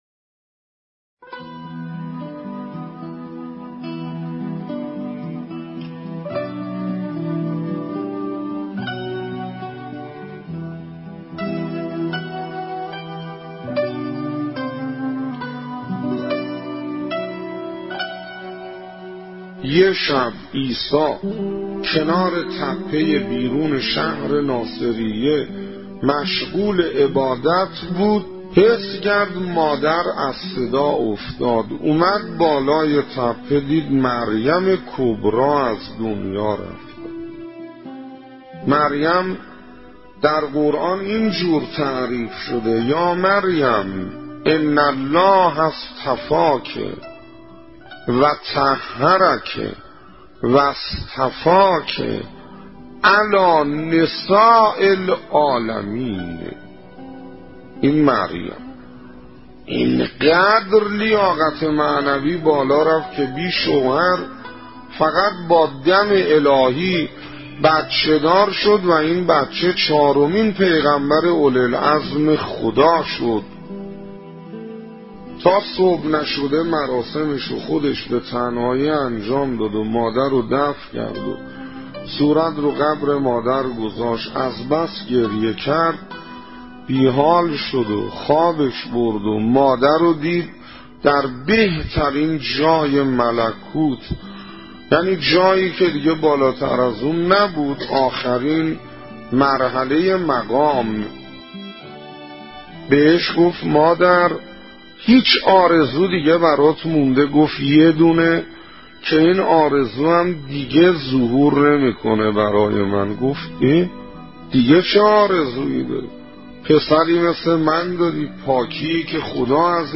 نواهنگ نماز از حجت الاسلام انصاریان